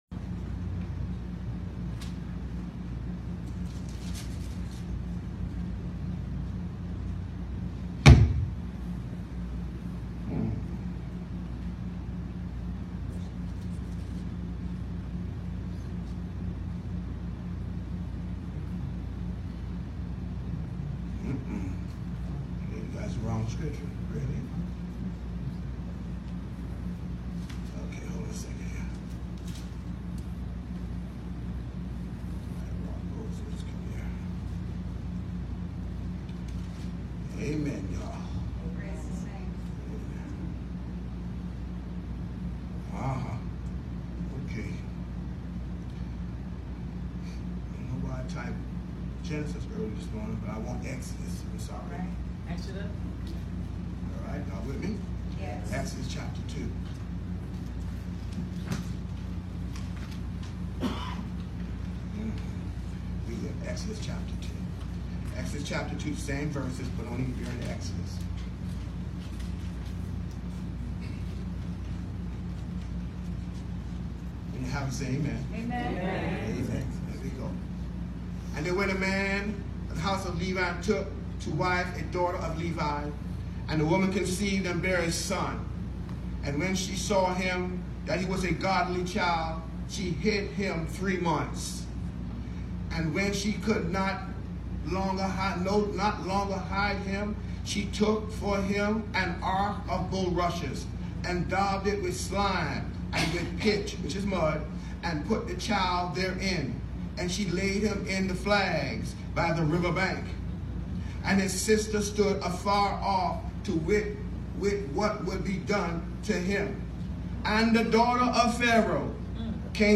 March 3, 2019 Sunday 11:00am New Jerusalem MB Church Exodus 2:1-5, 11-14 The Message: “Surviving The River”